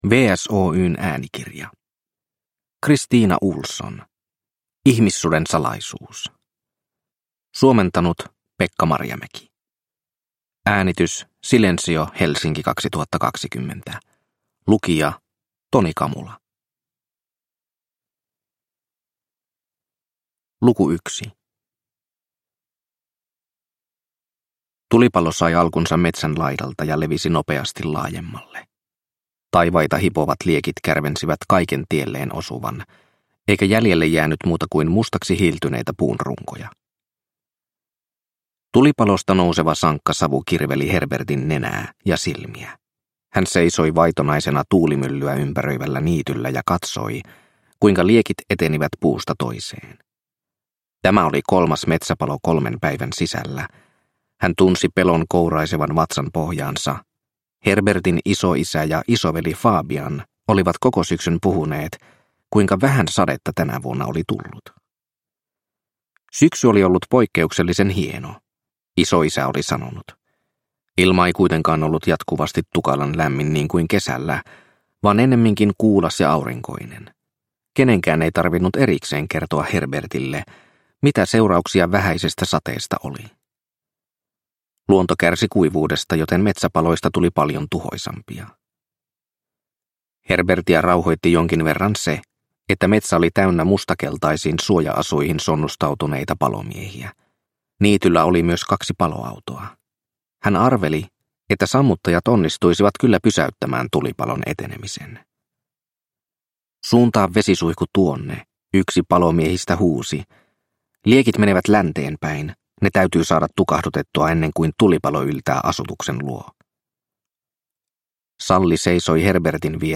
Ihmissuden salaisuus – Ljudbok – Laddas ner